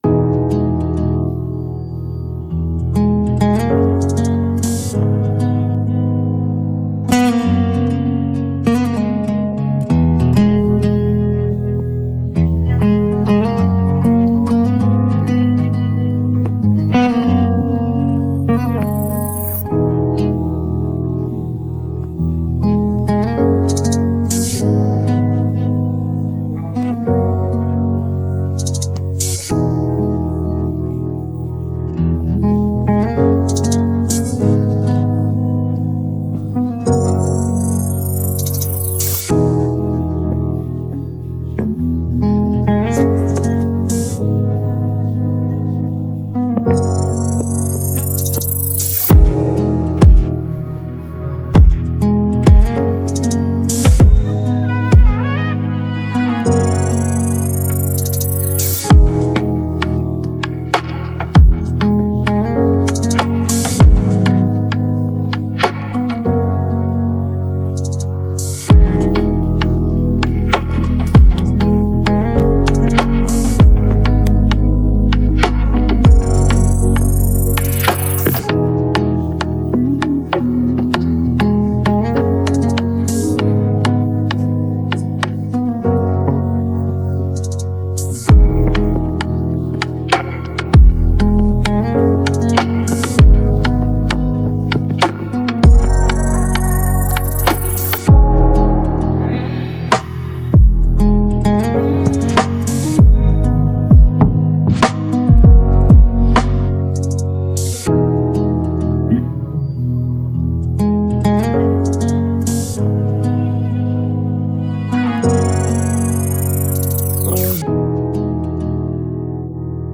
Afro dancehallDancehall